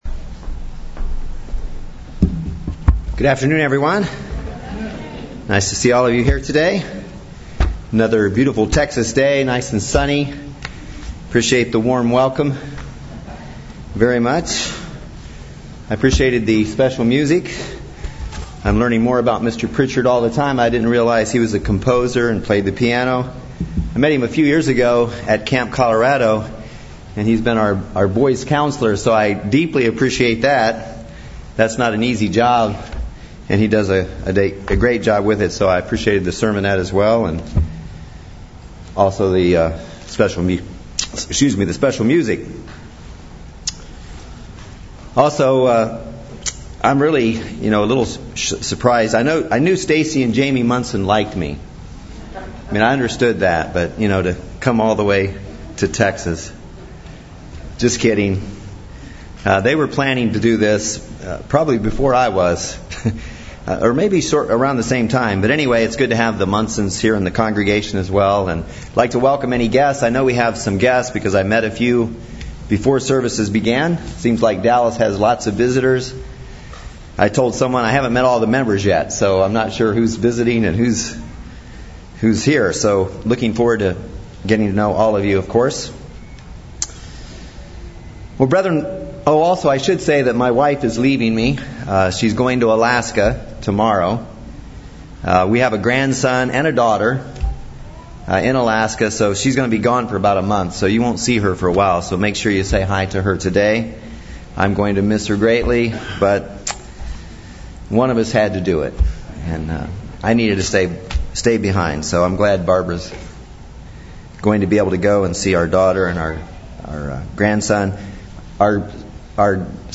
This sermon shows that most of us take God for granted one way or another.